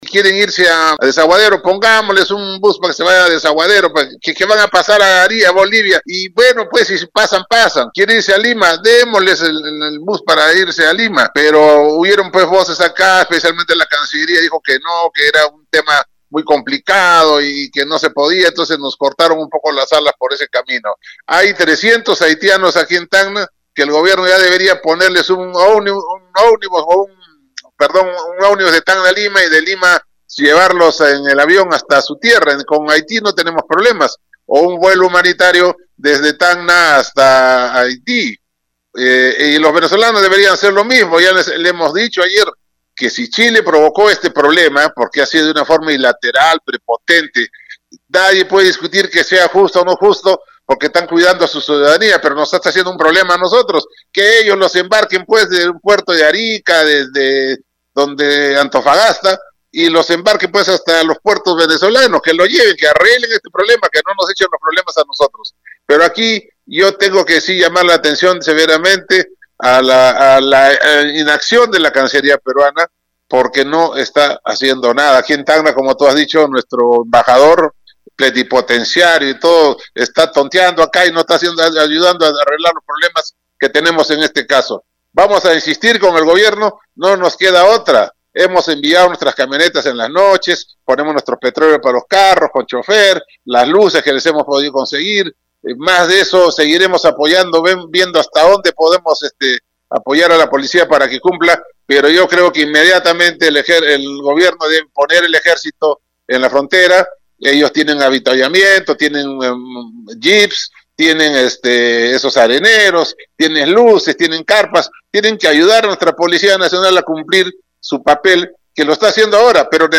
«Yo tengo que sí llamar la atención severamente a la inacción de la cancillería peruana porque no está haciendo nada, aquí en Tacna nuestro embajador plenipotenciario y todo está tonteando acá y no está ayudando a arreglar los problemas que tenemos en este caso», expresó este martes 25 vía Radio Uno.